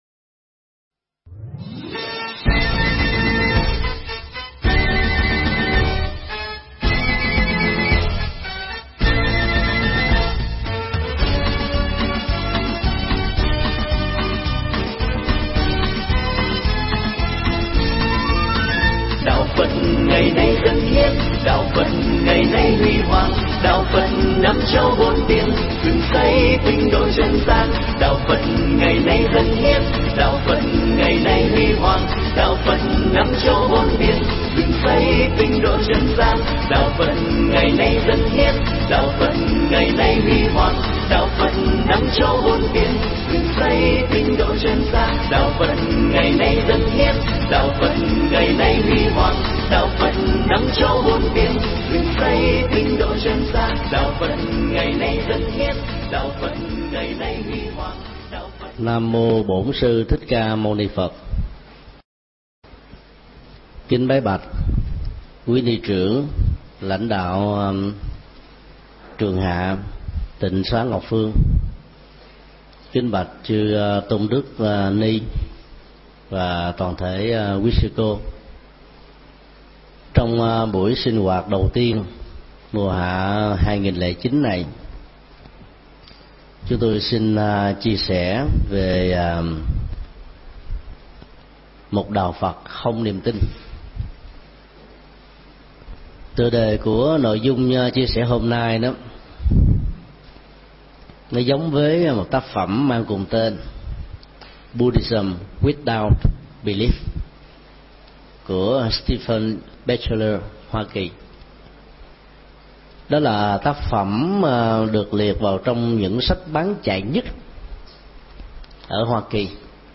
Pháp thoại Phật Giáo Không Tín Ngưỡng
thuyết pháp tại Trường Hạ Tịnh Xá Ngọc Phương